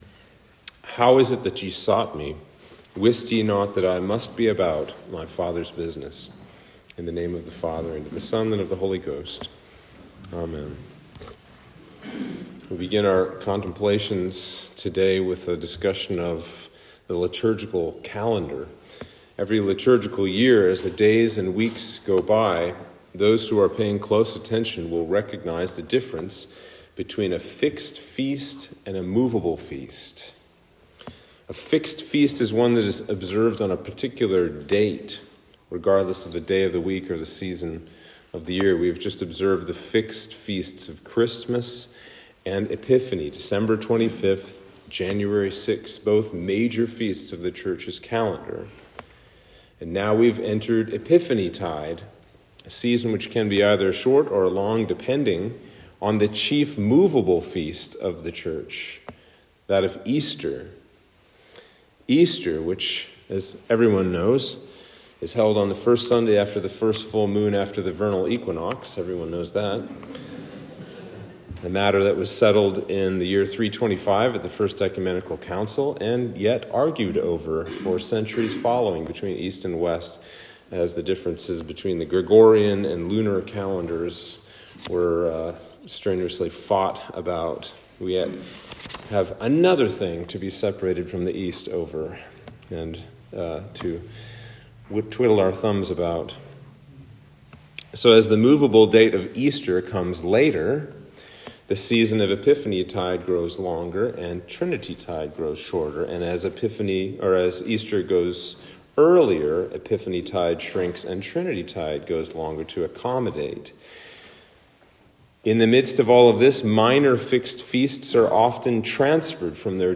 Sermon for Epiphany 1